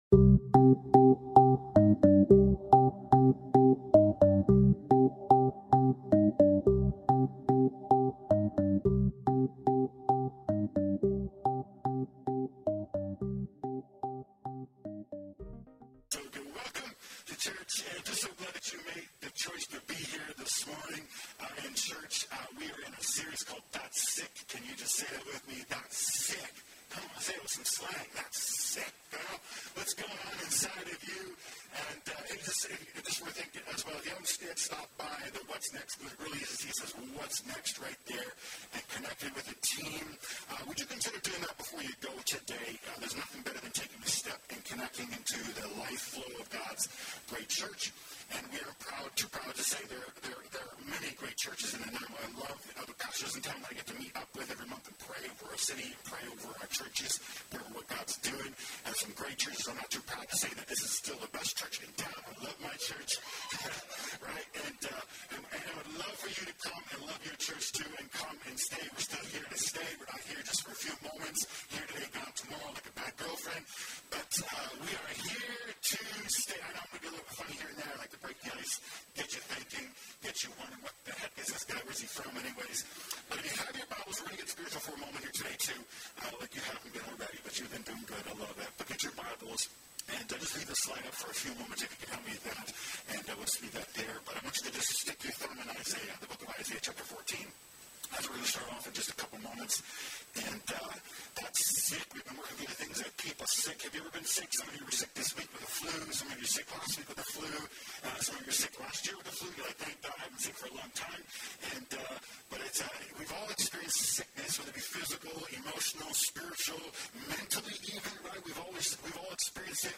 Download Download That's Sick Current Sermon What Do I Do With My Pride?